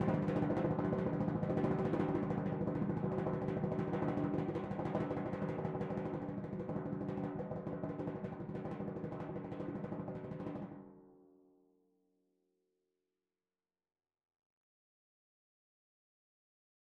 Timpani5_Roll_v4_rr1_Sum.wav